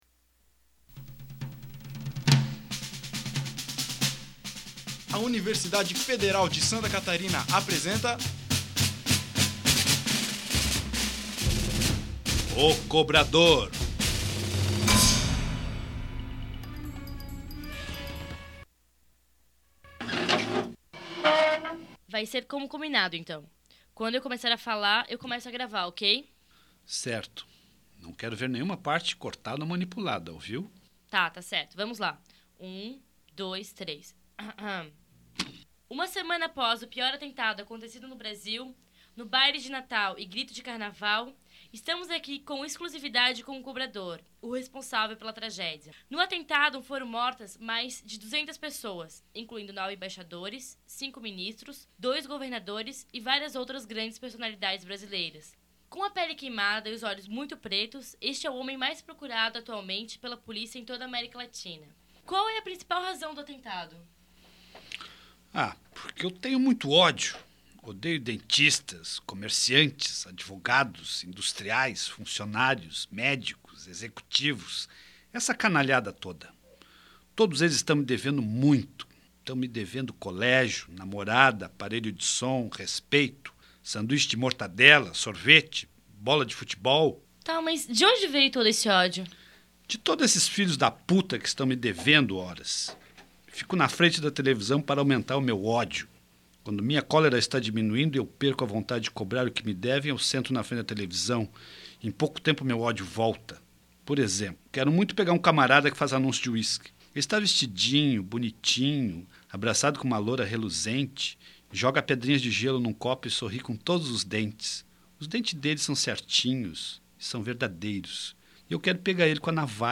Radioteatro